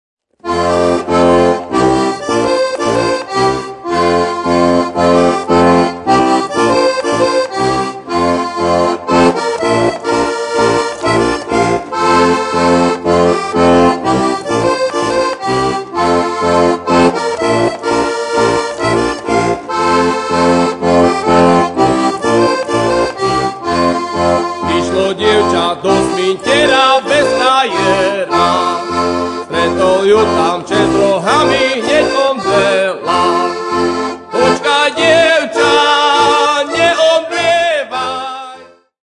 Kategória: Ľudová hudba